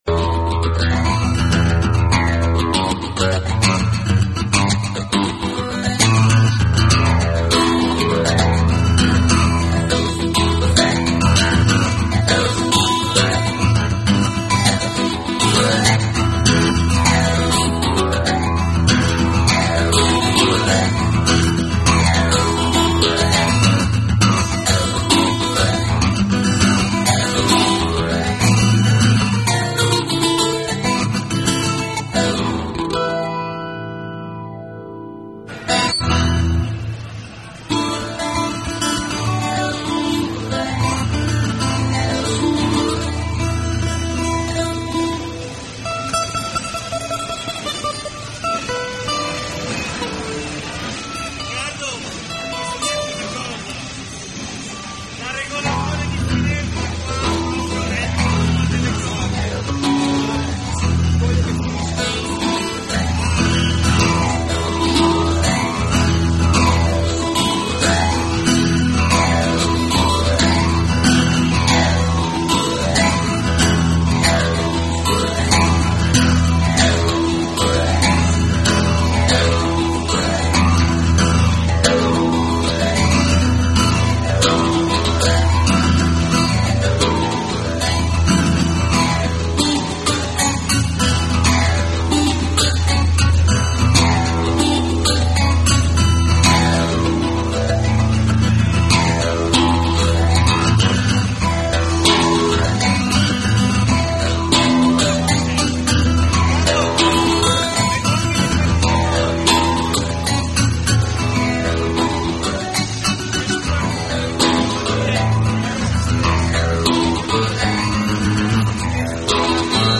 The SINK #94 – Quella coscia che era meglio coprire (era: LIVE @ MEI 2009 1°giorno) | Radio NK